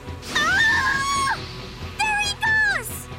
I think this is supposed to be a country accent